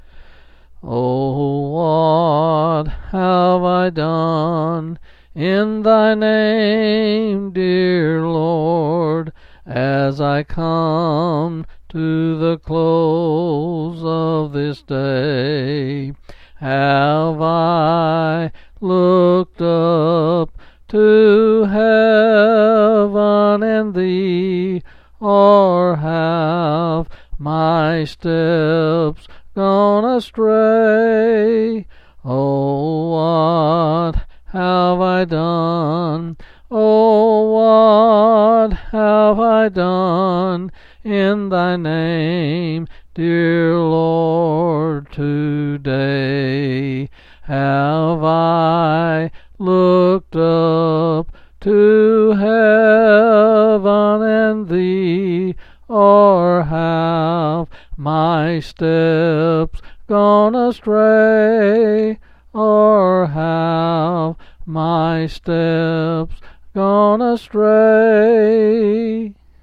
Quill Pin Selected Hymn